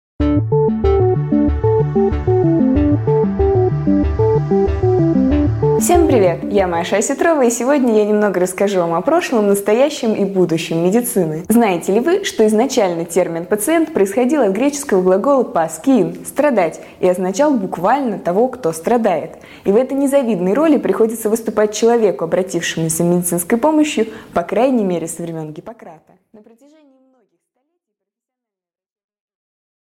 Аудиокнига 5 минут О медицине | Библиотека аудиокниг